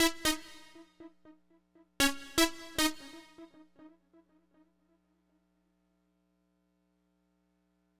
Power Pop Punk Keys Ending.wav